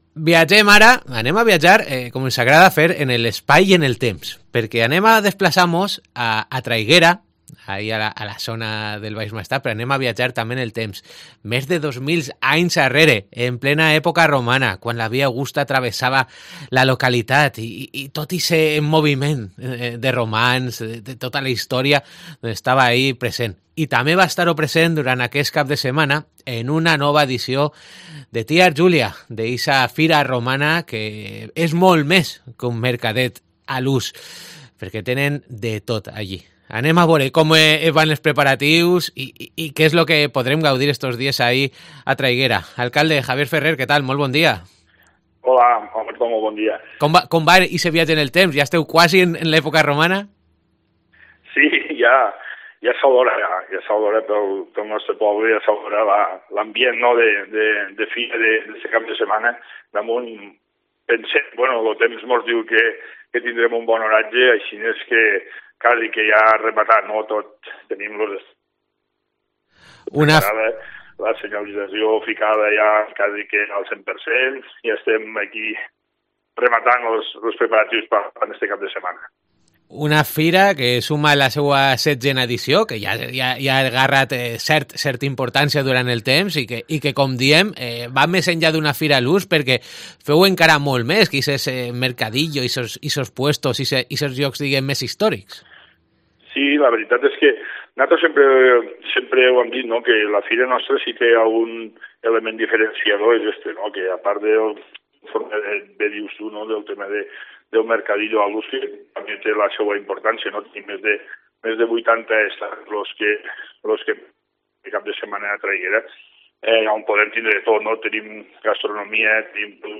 El alcalde de Traiguera, Javier Ferrer, nos presenta los detalles de la nueva edición de la Feria Romana, que celebra este sábado y domingo.